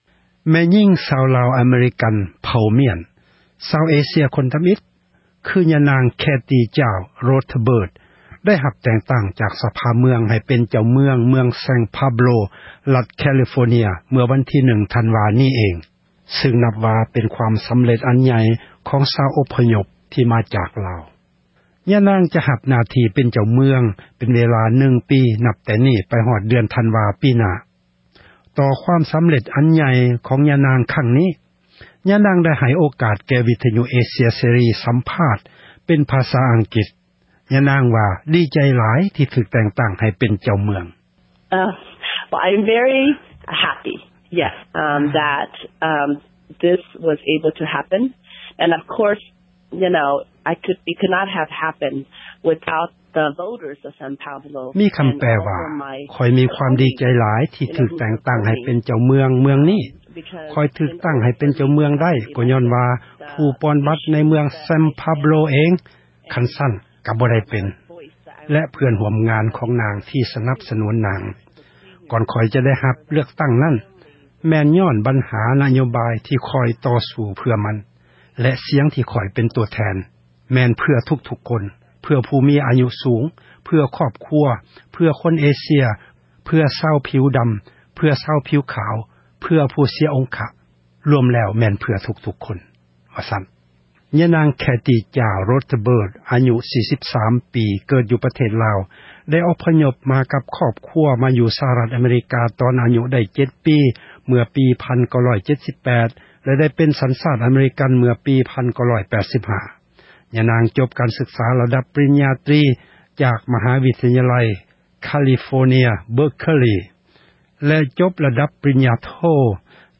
ຍານາງ ຈະຮັບ ໜ້າທີ່ ເປັນ ເຈົ້າເມືອງ ເປັນເວລາ 1 ປີ ນັບ ແຕ່ນີ້້ ໄປຮອດ ເດືອນ ທັນວາ ປີໜ້າ. ຍານາງ ໄດ້ໃຫ້ ນັກຂ່າວ ວິທຍຸ ເອເຊັຽ ສັມພາດ ຊຶ່ງເປັນ ພາສາ ອັງກິດ ທີ່ ມີໃຈ ຄວາມ ດັ່ງນີ້: